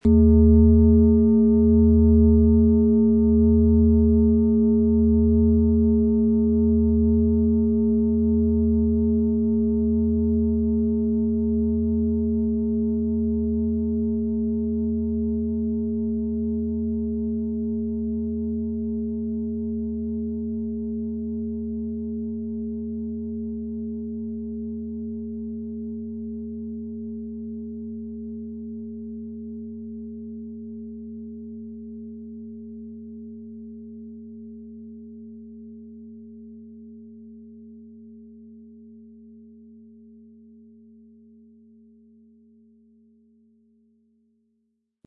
• Tiefster Ton: Venus
• Höchster Ton: Mond
Wie hört sich der Klang der Schale an?
Durch die traditionsreiche Fertigung hat die Schale vielmehr diesen kraftvollen Ton und das tiefe, innere Berühren der traditionellen Handarbeit
PlanetentöneAlphawelle & Venus & Mond (Höchster Ton)
MaterialBronze